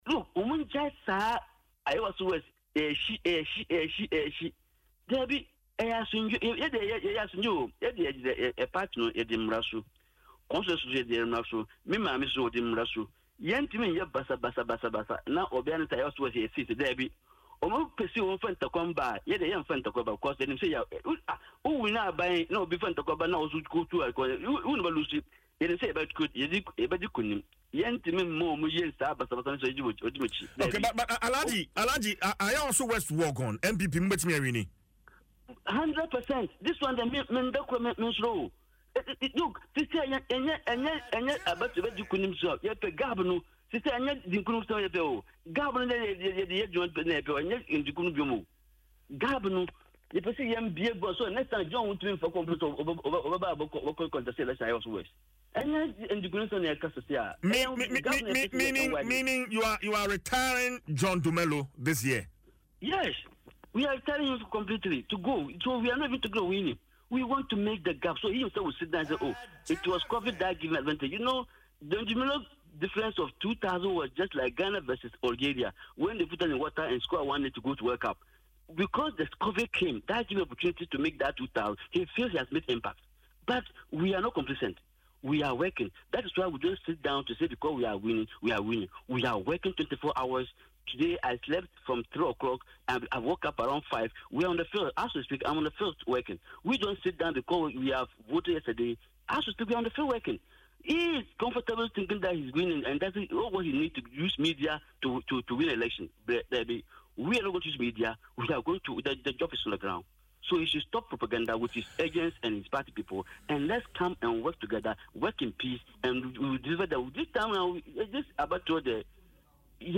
However, the NPP Chairman in an interview on Adom FM’s morning Dwaso Nsem Tuesday attributed Mr Dumelo’s victory to the Covid-19 pandemic.